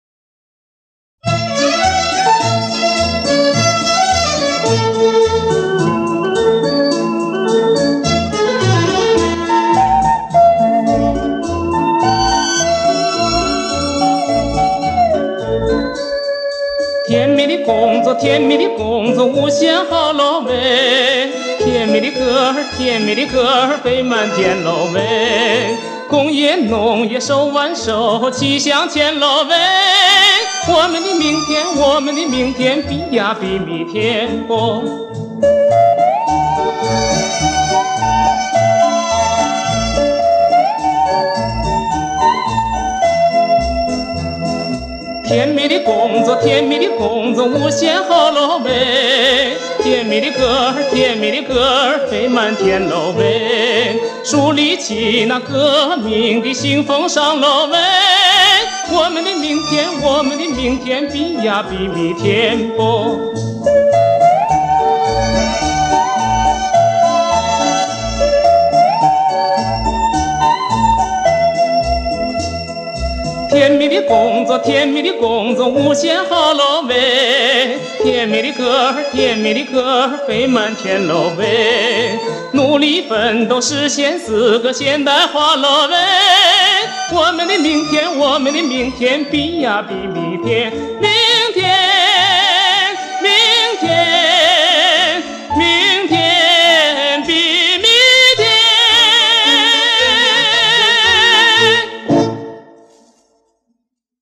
介质：LP 两张四面